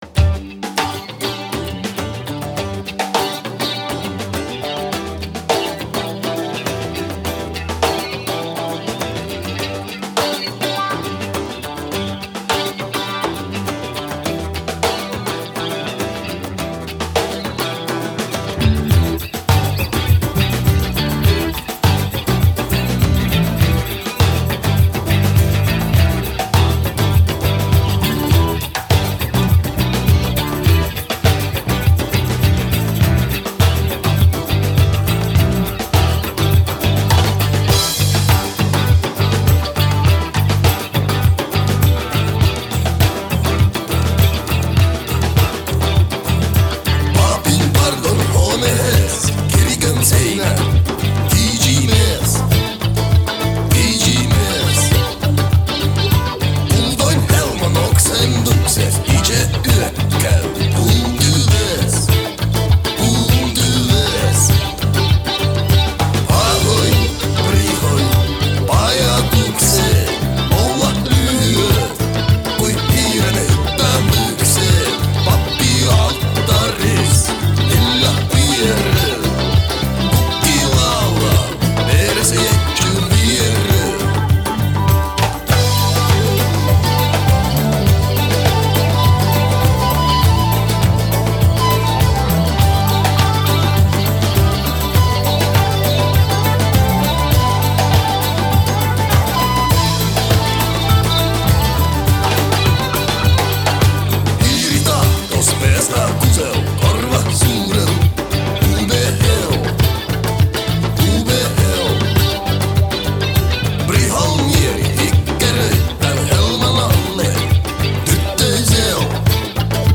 pajatandu, bass-gituaru, taganpajatandu
electrogituaru, gituaru, örineh
buzuki, huuligarmoniekku, garmoniekku, taganpajatandu
barabanat